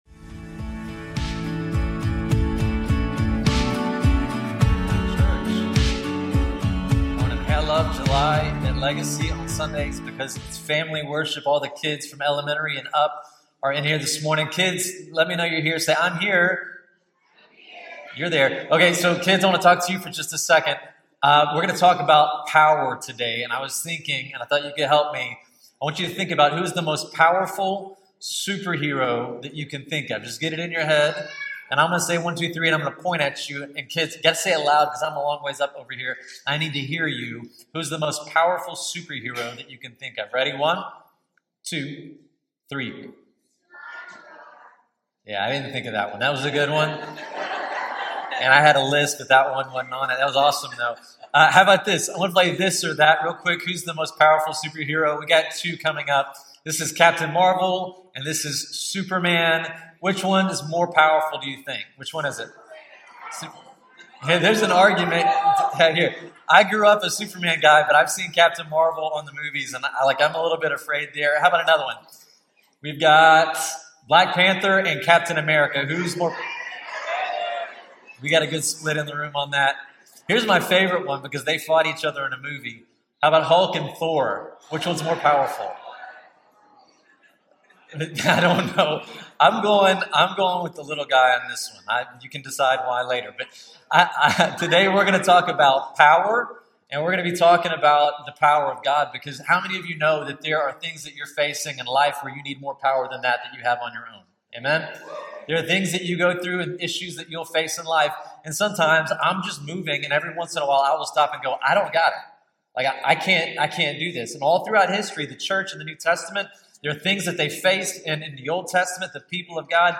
Through a collection of real-life examples, Scripture reflections, and honest prayer, the sermon highlights three key truths: